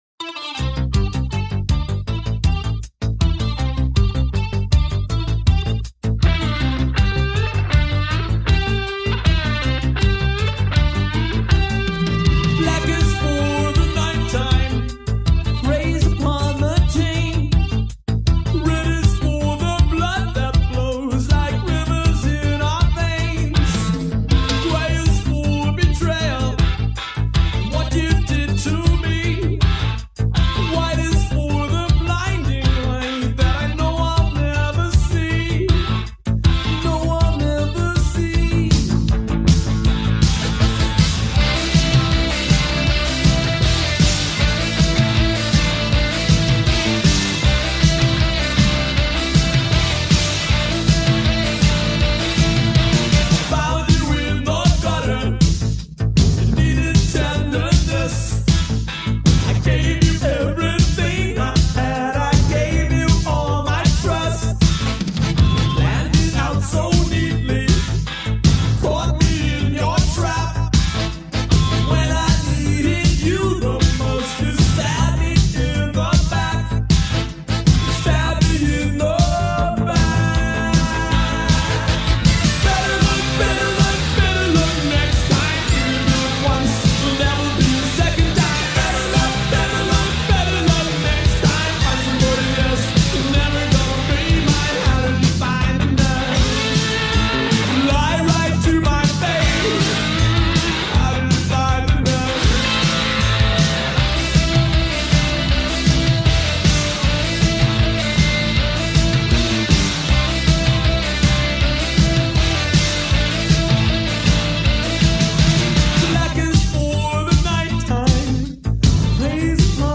One of the best new wave bands of its time